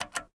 AnchorButton.ogg